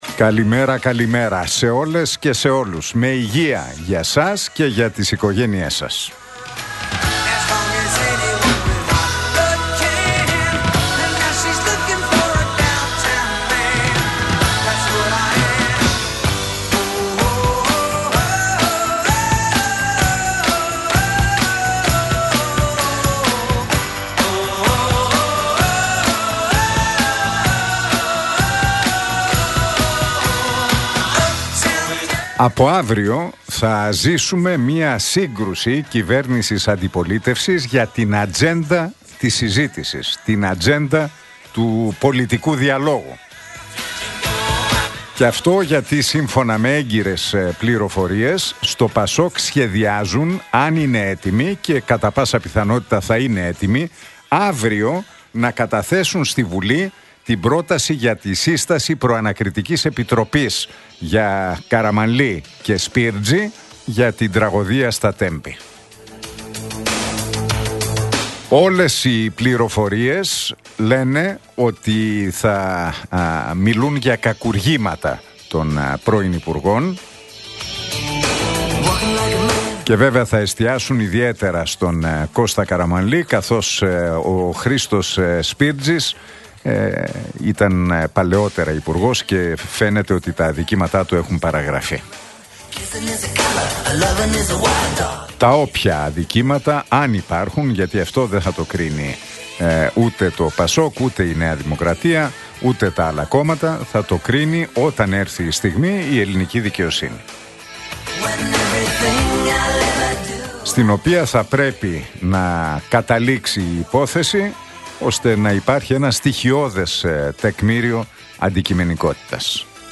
Ακούστε το σχόλιο του Νίκου Χατζηνικολάου στον ραδιοφωνικό σταθμό Realfm 97,8, την Πέμπτη 8 Μαΐου 2025.